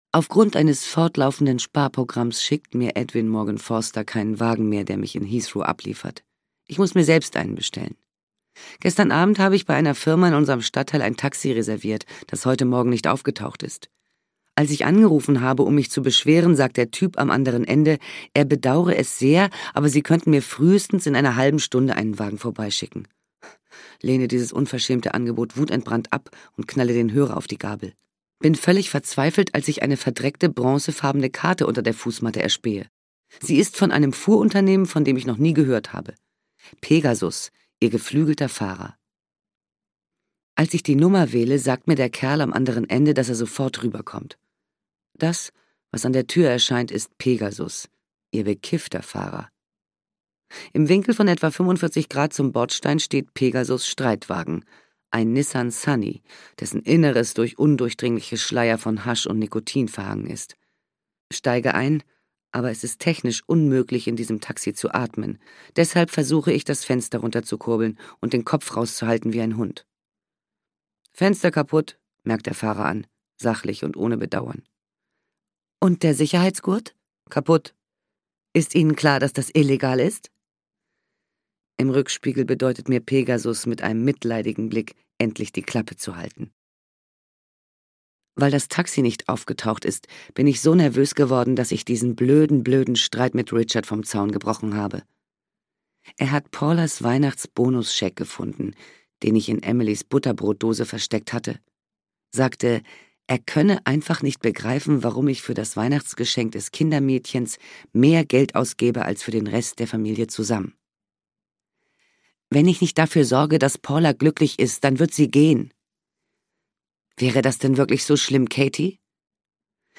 Working Mum - Allison Pearson - Hörbuch